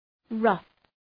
Προφορά
{rʌf}